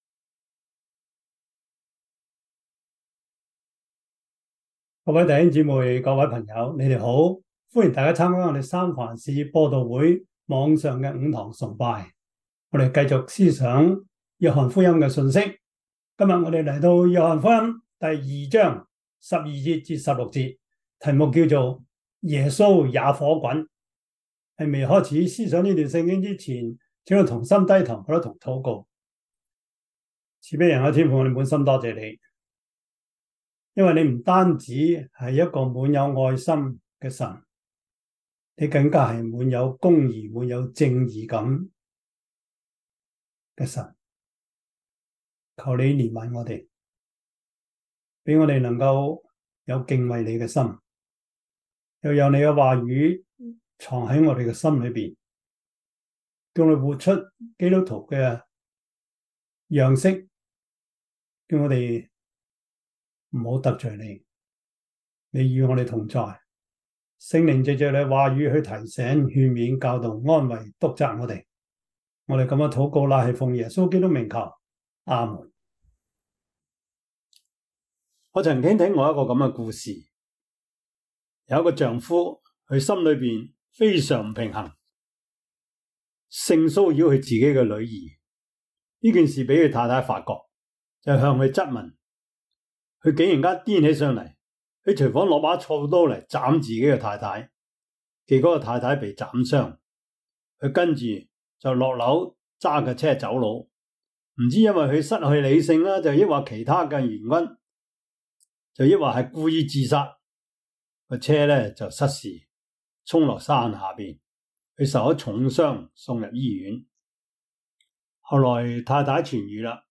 約翰福音 2:12-16 Service Type: 主日崇拜 約翰福音 2:12-16 Chinese Union Version
」 Topics: 主日證道 « 貪之過 第十六課: 基督徒與愛關及脫貧 »